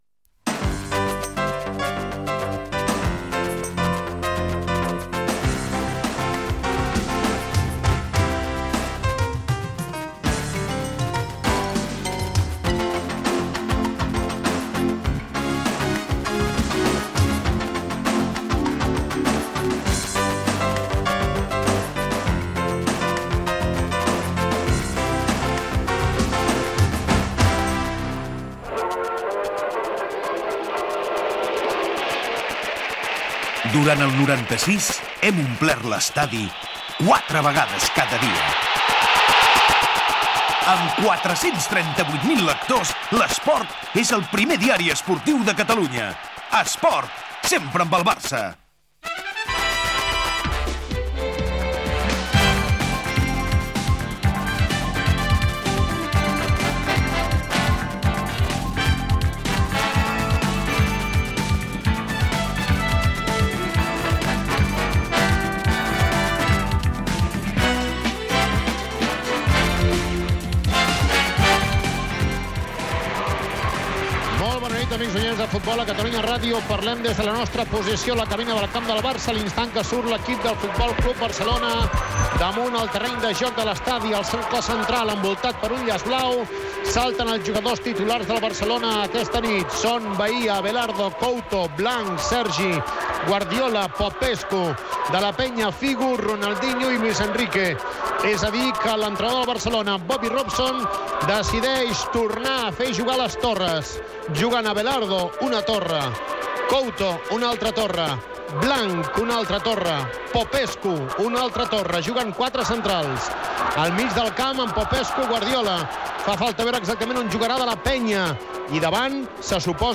Sintonia de l'emissora, publicitat, inici de la transmissió del partit F.C. Barcelona - Atlètic de Madrid. Alineacions i primeres jugades.
Esportiu